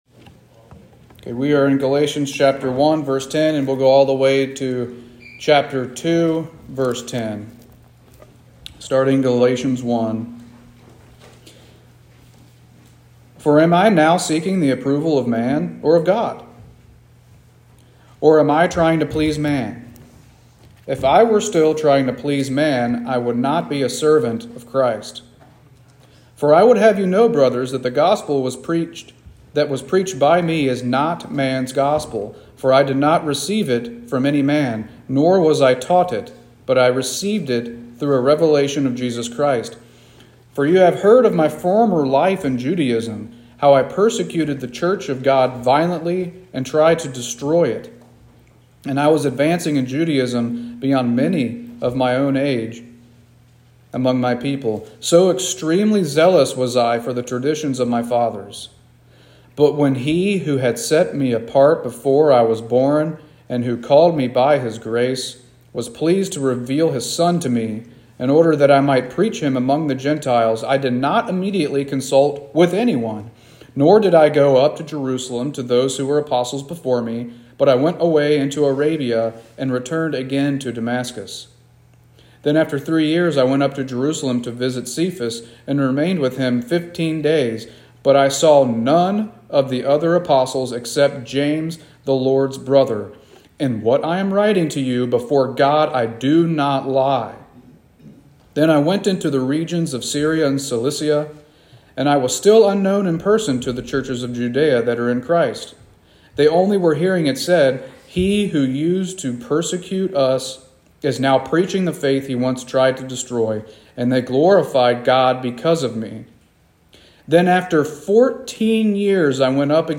Sermons | Monterey Baptist Church